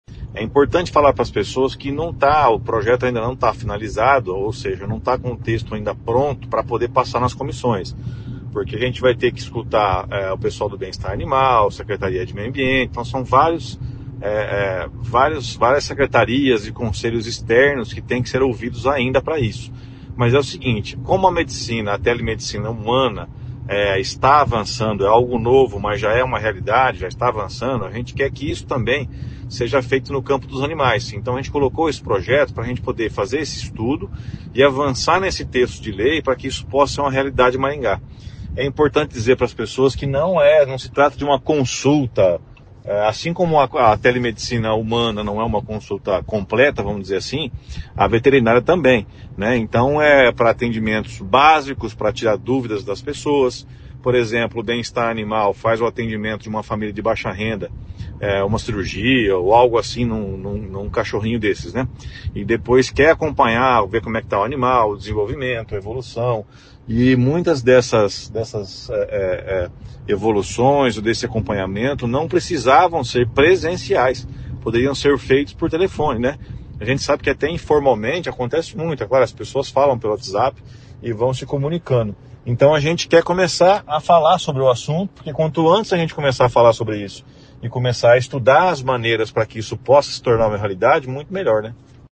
Em entrevista ao GMC Online, o vereador Flávio Mantovani destacou a importância do projeto, mas afirmou que ainda está em fase de discussão e aprimoramento.